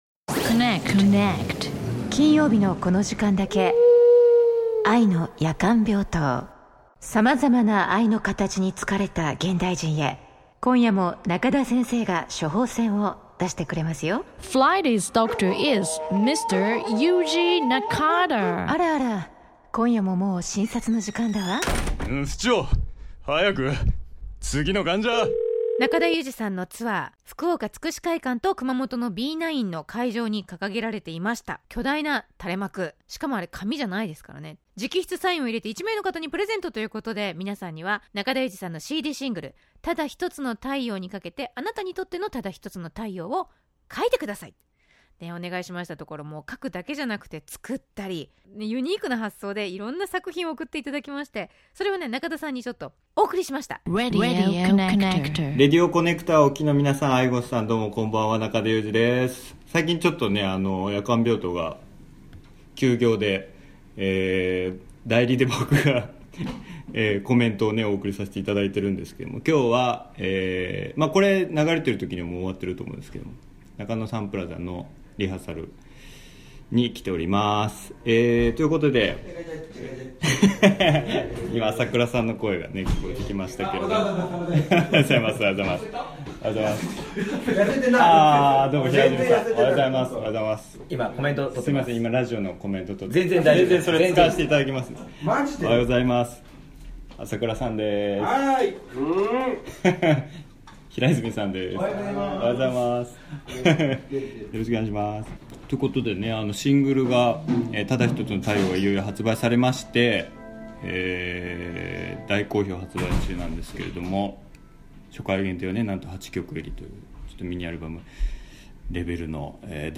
ライブ直前のインタビュー後編